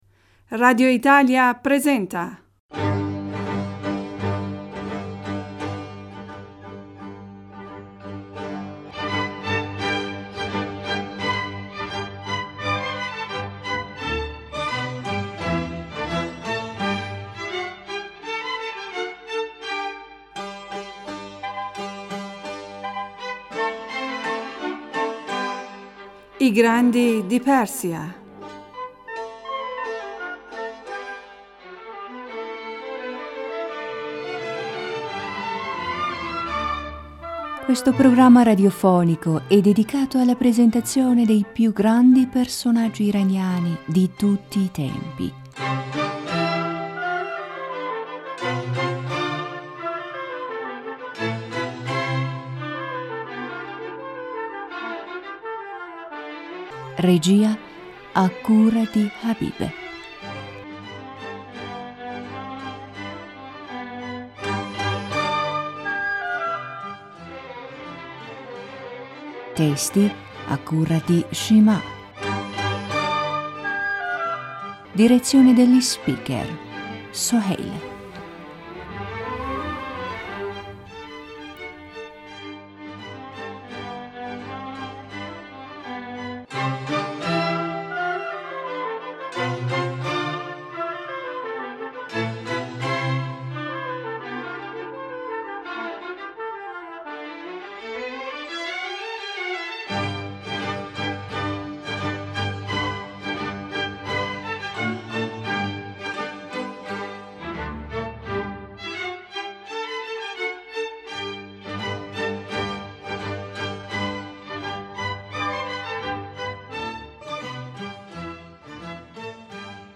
Siamo con voi con un’altRa puntata della rappresentazione radiofonica” I grandi della P...